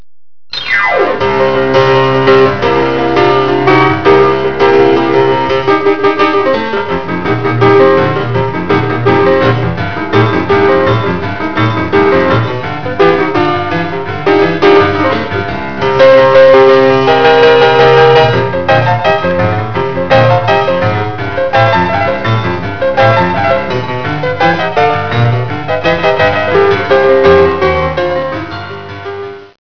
Boogiewoogie piano